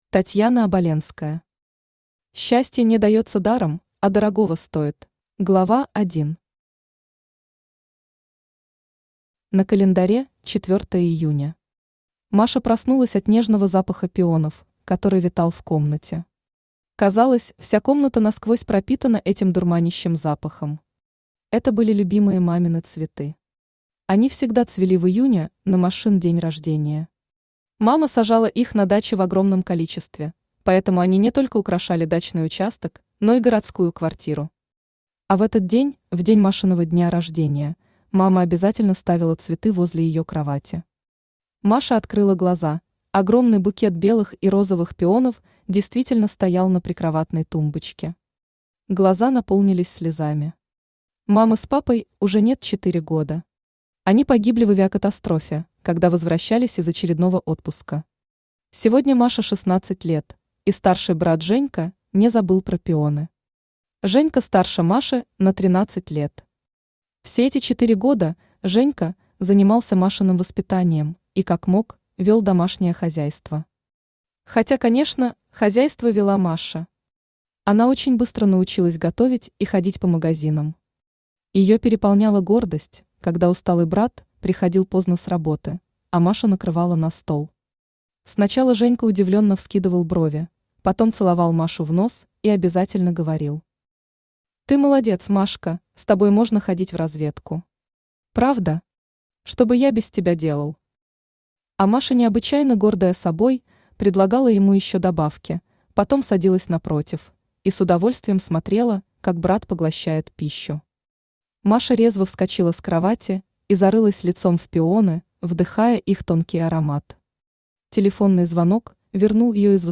Аудиокнига Счастье не дается даром, а дорогого стоит | Библиотека аудиокниг
Aудиокнига Счастье не дается даром, а дорогого стоит Автор Татьяна Оболенская Читает аудиокнигу Авточтец ЛитРес.